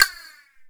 Percussion #09.wav